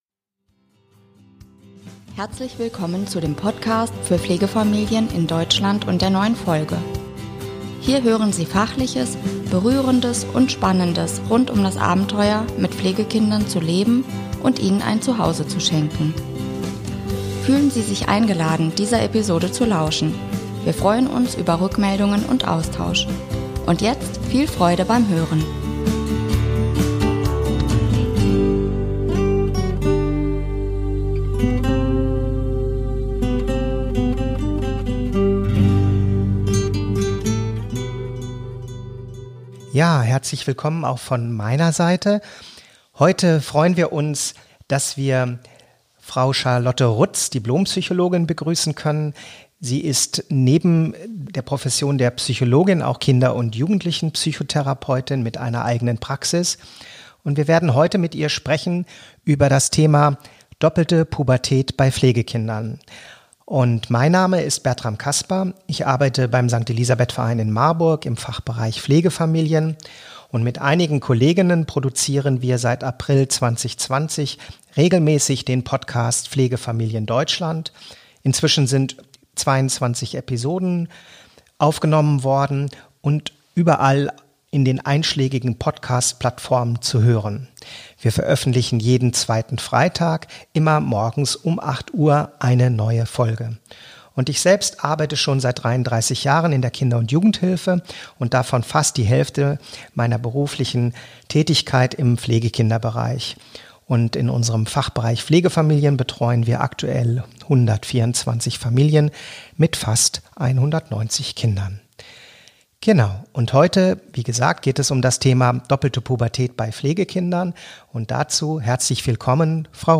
Heute sprechen wir mit der Dipl. Psychologin und Kinder- und Jugendpsychotherapeutin